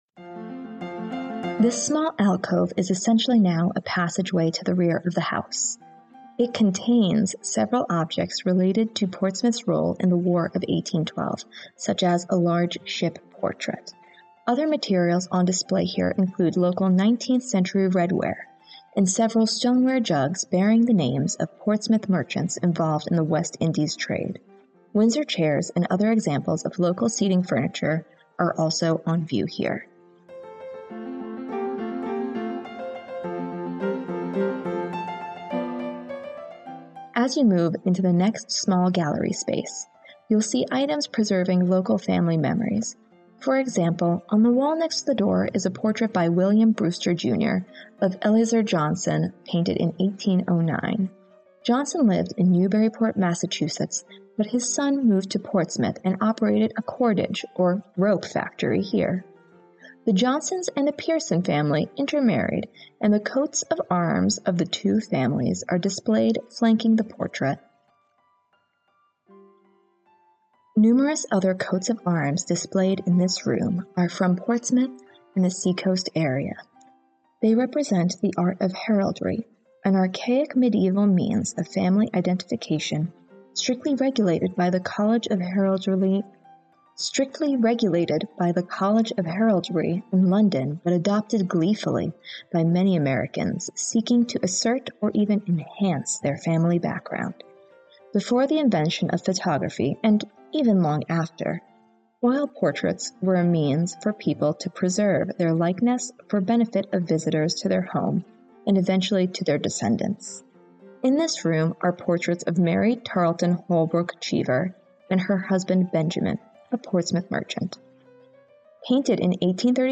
Audio Guide Small Gallery